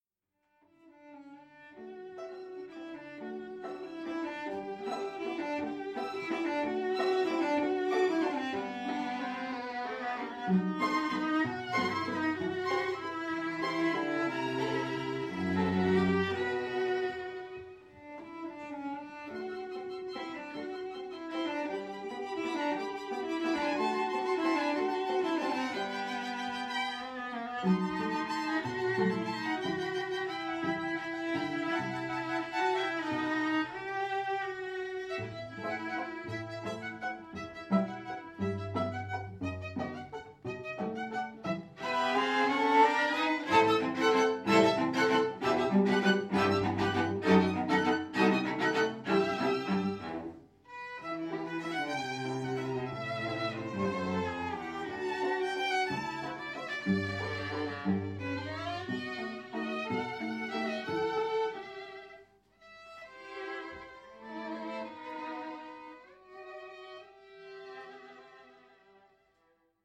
22/05/2016 20:00 Vendredi dernier, le quatuor Winston jouait sur la scène du LarOcafé. Assis sur des tabourets, un verre de vin à la main, le public avait les yeux brillants.